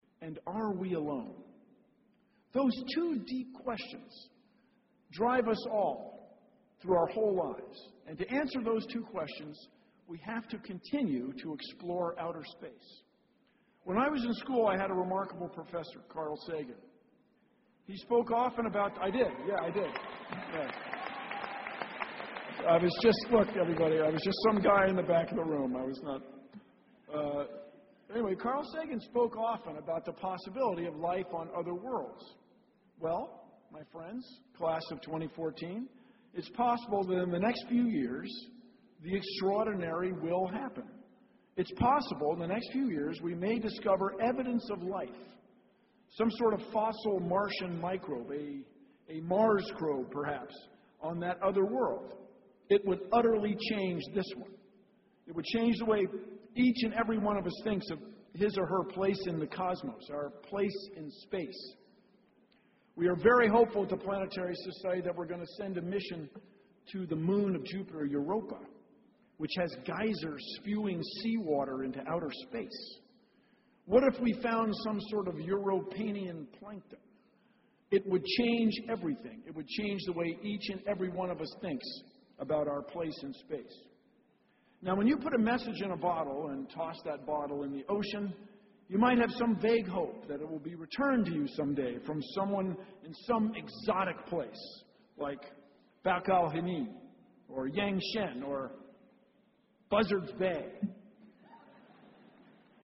公众人物毕业演讲 第170期:比尔·奈马萨诸塞大学2014(17) 听力文件下载—在线英语听力室